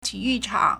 体育场 (體育場) tǐyù chǎng
ti3yu4chang3.mp3